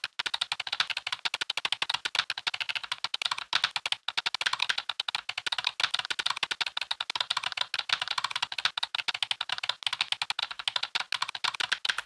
typing.mp3